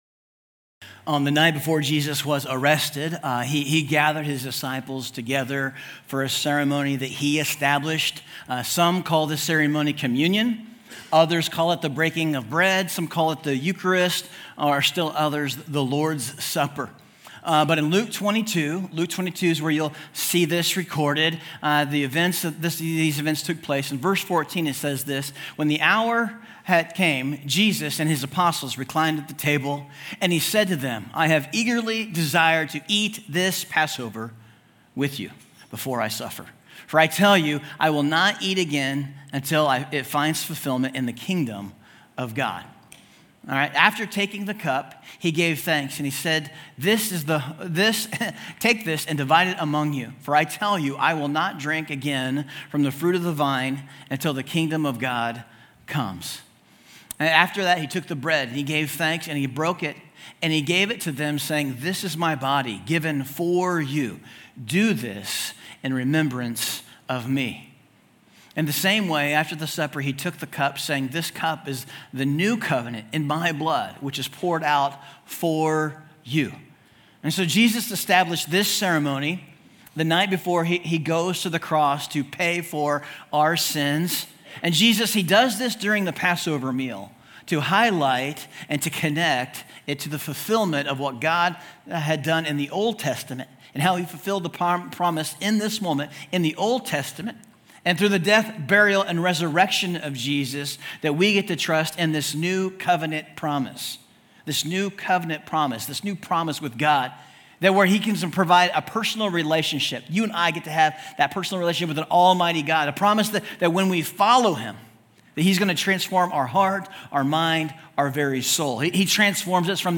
Communion_Service_2024_Message_Audio.mp3